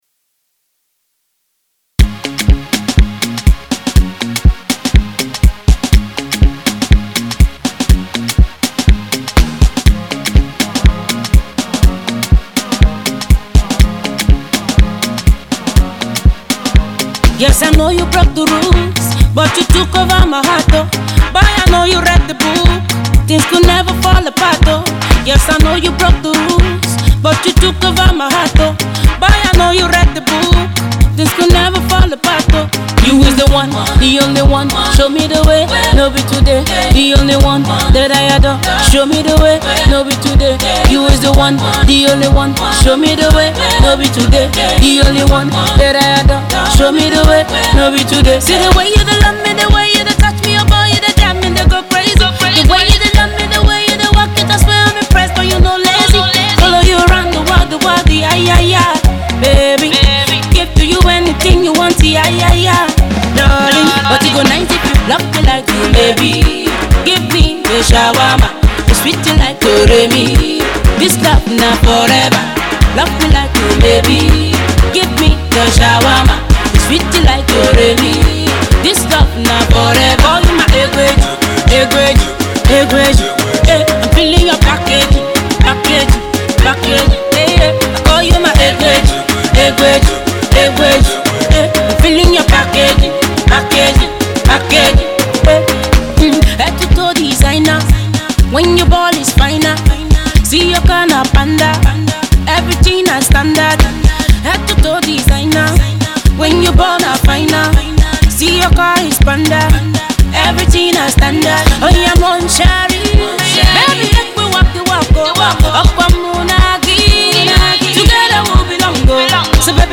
’a lovely tune that can get you moving your body any day.